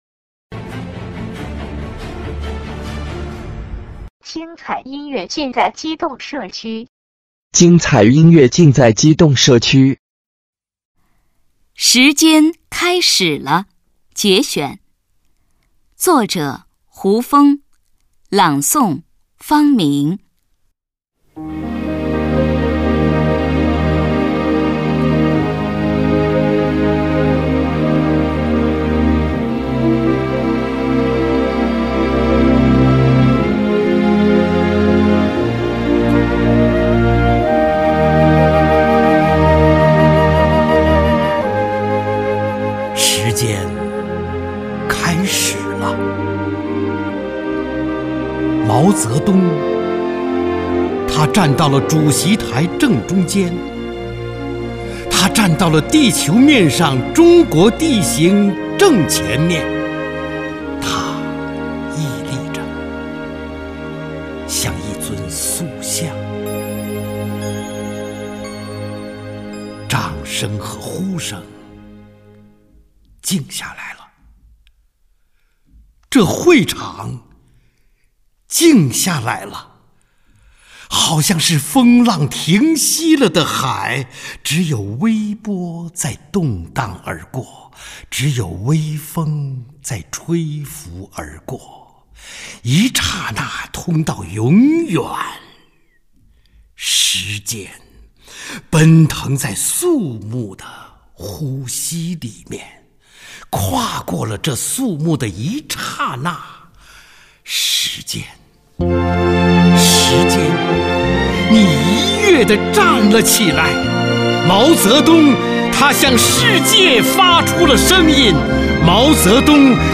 中央人民广播电台播音员 主持人倾情朗诵